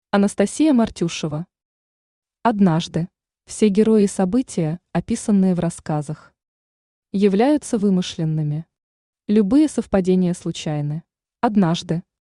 Аудиокнига Однажды | Библиотека аудиокниг
Aудиокнига Однажды Автор Анастасия Ивановна Мартюшева Читает аудиокнигу Авточтец ЛитРес.